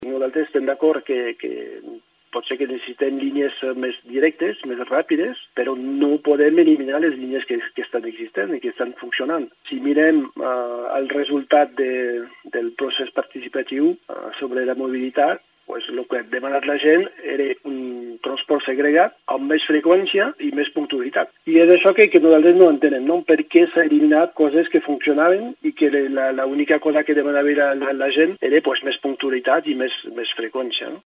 als micròfons d’aquesta casa.